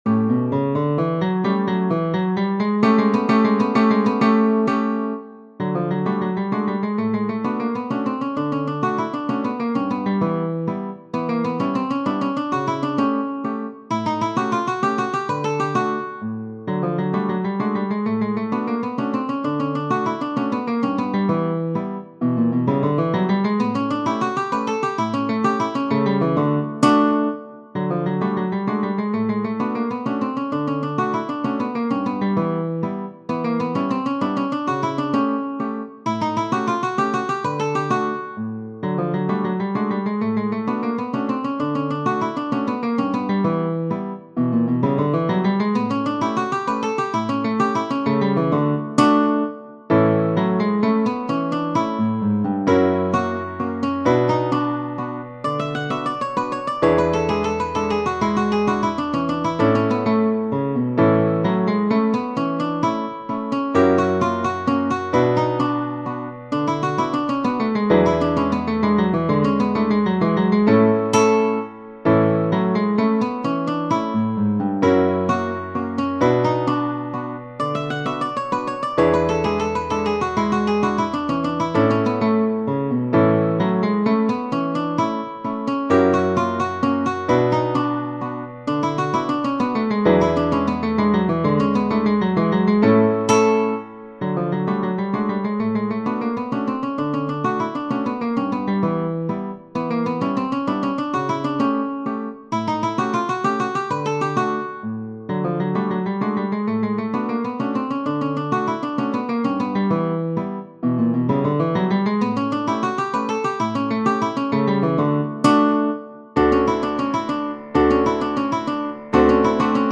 Capitani, G. Genere: Ballabili "La doccia" è una popolare mazurka scritta e pubblicata nel 1867 dal compositore e pianista italiano Giuseppe Capitani.